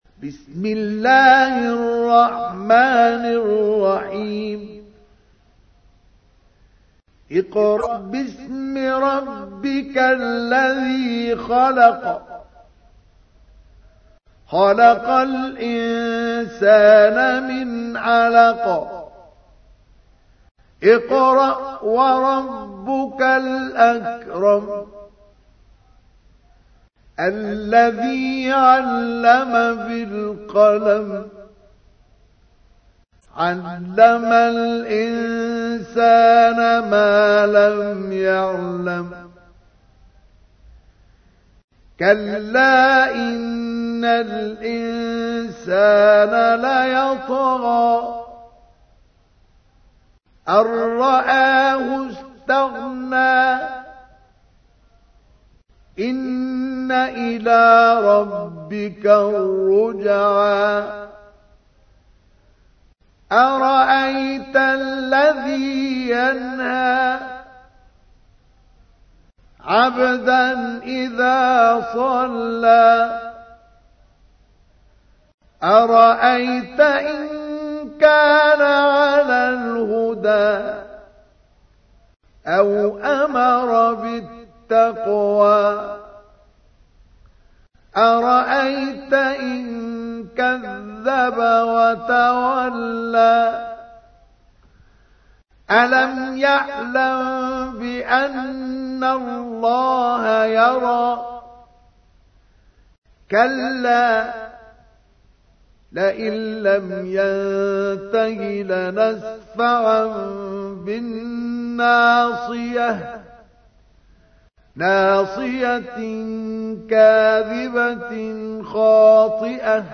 تحميل : 96. سورة العلق / القارئ مصطفى اسماعيل / القرآن الكريم / موقع يا حسين